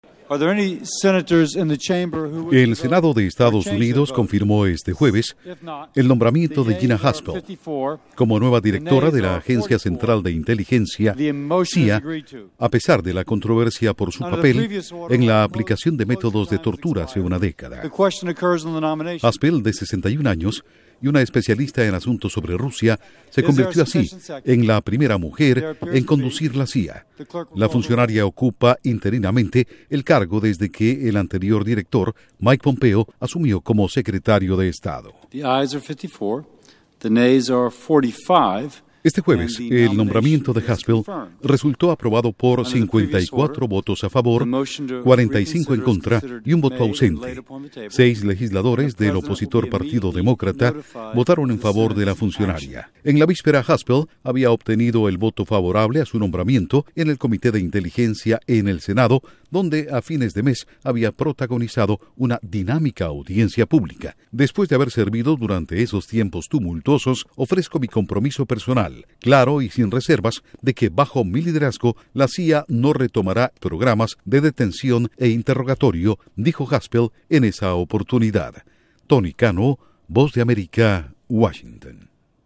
Escuche el informe desde la Voz de América